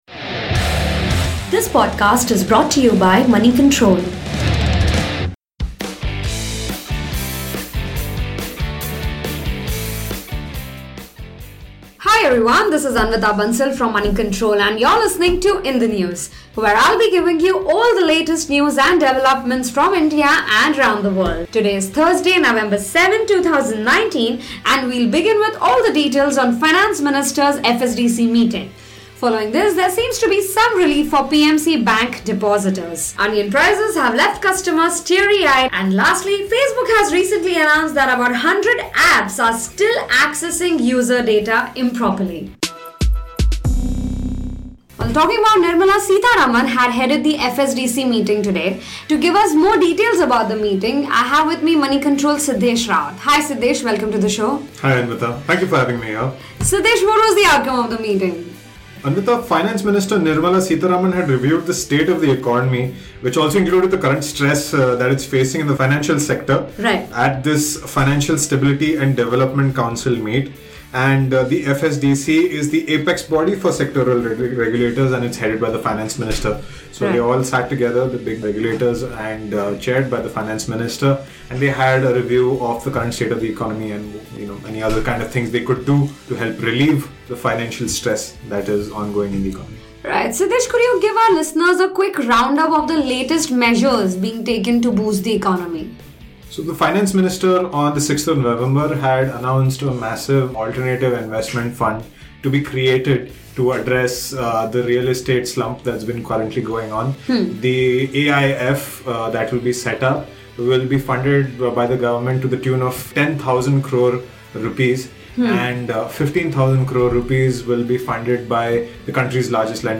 talks to the Moneycontrol news desk